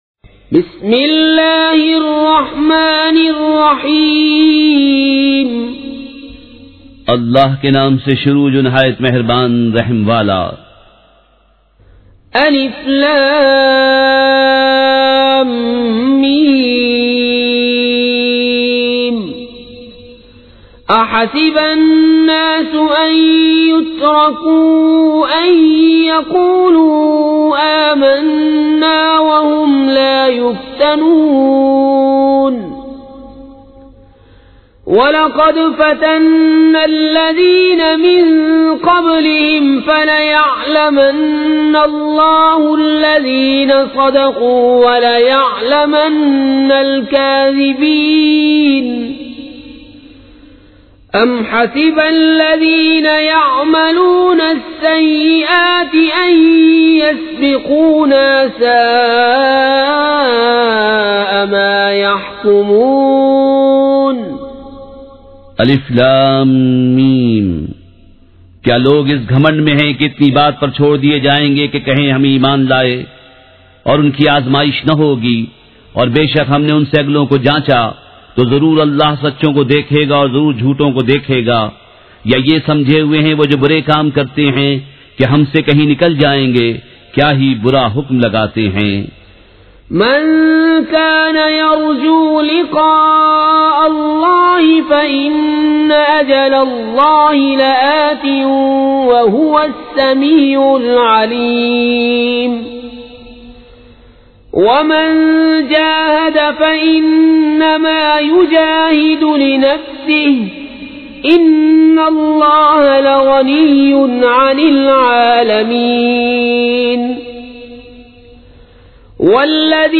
سورۃ العنکبوت مع ترجمہ کنزالایمان ZiaeTaiba Audio میڈیا کی معلومات نام سورۃ العنکبوت مع ترجمہ کنزالایمان موضوع تلاوت آواز دیگر زبان عربی کل نتائج 3398 قسم آڈیو ڈاؤن لوڈ MP 3 ڈاؤن لوڈ MP 4 متعلقہ تجویزوآراء